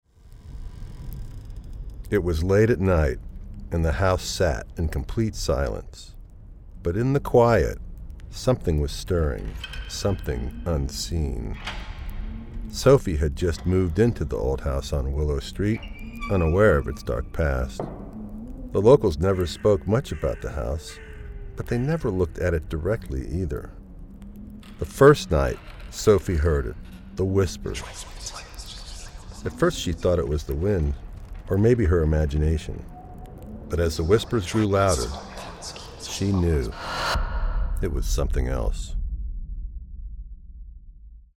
Narration - Shadows behind the Door
USA English, midwest
Middle Aged
I work from a broadcast-quality home studio.